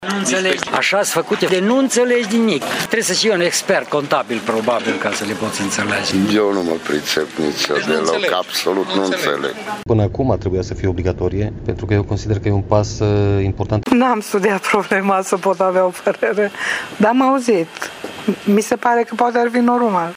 Mulți dintre târgumureșeni sunt derutați de noile reglementări, pe care nu le înțeleg complet, dar nu resping ideea eliminării tranzacțiilor mari cu bani cash.